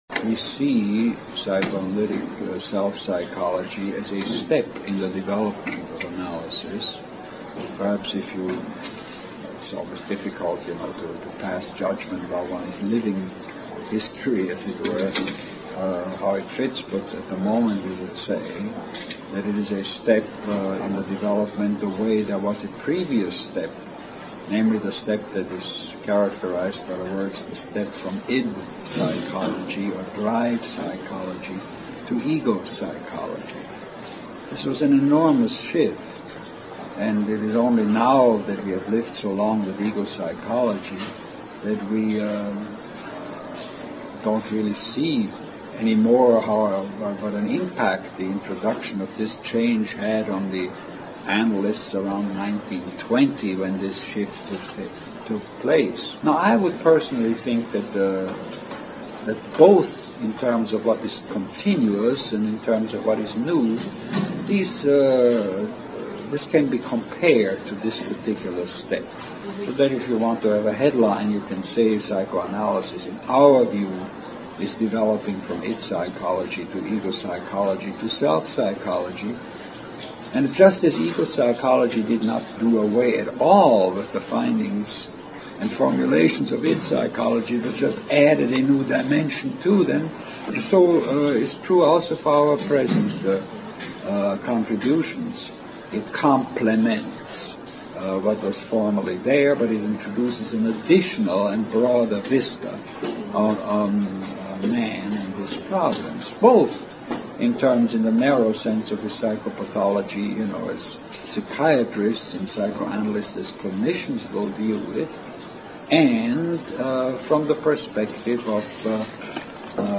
KohutInterview_Audio.mp3